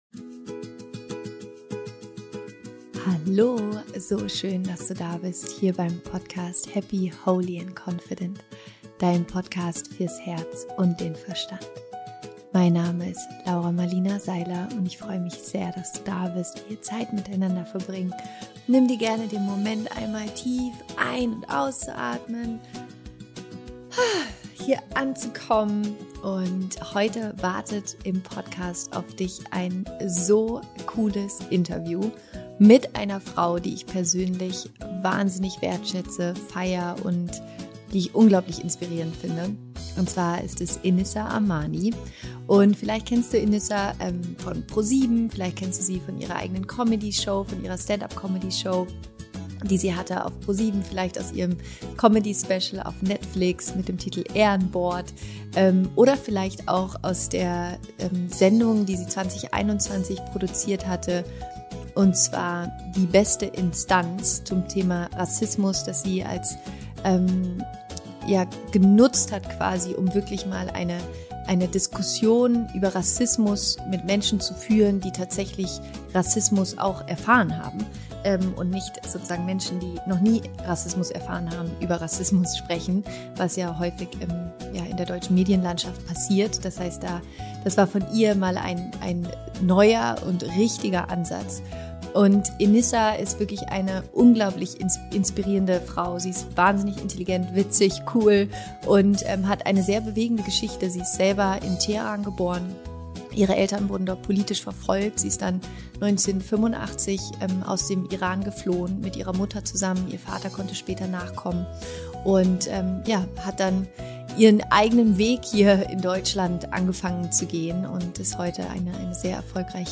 Im Interview sprechen wir darüber, wie sie ihre Kindheit erlebt hat und warum sie sich heute einsetzt, um über Rassismus aufzuklären.